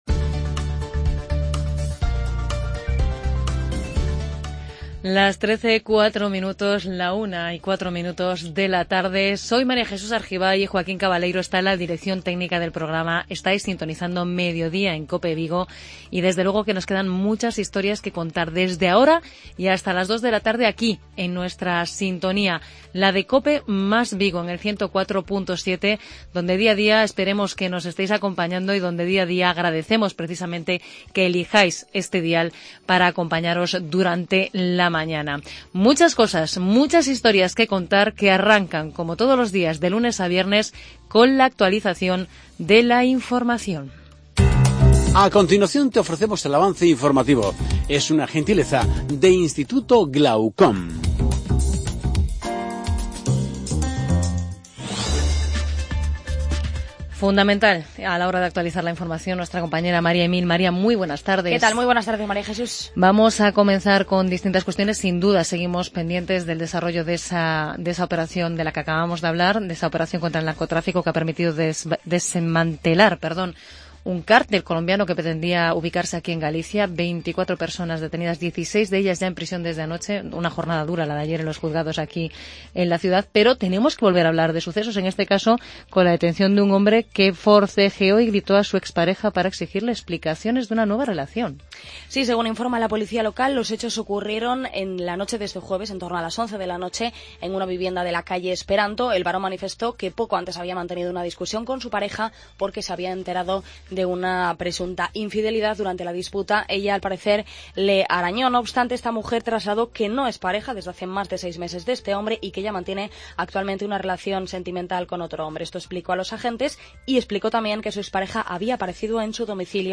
Resumen de la semana con las voces de los protagonistas.